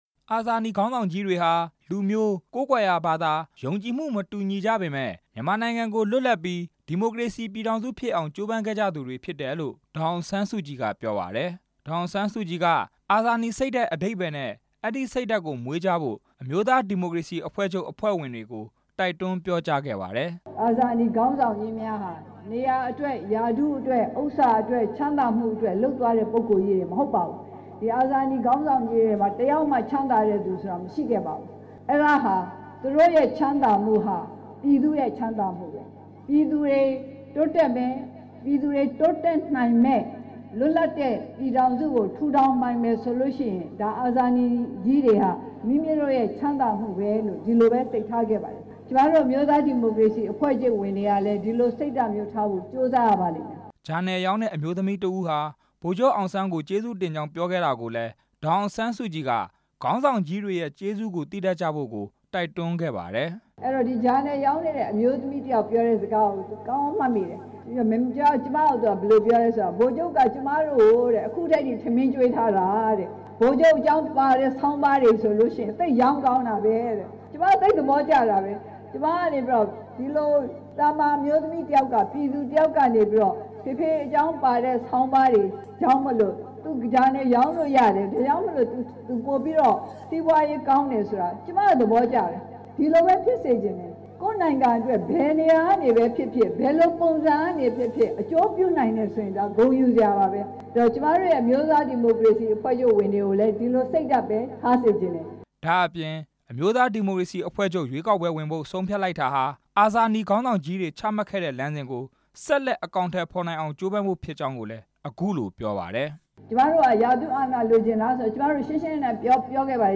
ဒီကနေ့ ကျရောက်တဲ့ ၆၈ နှစ်မြောက် အာဇာနည်နေ့ အခမ်းအနားကို ရန်ကုန်မြို့ အမျိုးသားဒီမိုကရေစီအဖွဲ့ချုပ်ရုံးမှာ ကျင်းပခဲ့ပါတယ်။ အမျိုးသားဒီမိုကရေစီ အဖွဲ့ချုပ် ဥက္ကဌ ဒေါ်အောင်ဆန်းစုကြည်က အာဇာနည်စိတ်ဓာတ်နဲ့ အမျိုးသားဒီမိုကရေစီအဖွဲ့ချုပ်ရဲ့ ရပ်တည်ချက်အကြောင်းတွေကို ထည့်သွင်းပြောကြားခဲ့ပါတယ်။